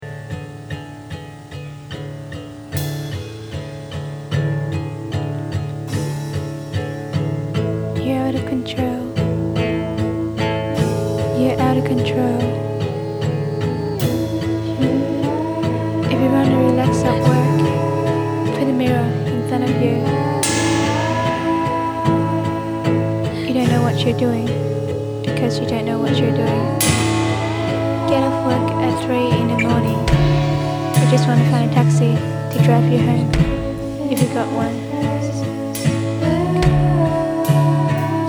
Indie-pop/rock/experimenta/lo-fi project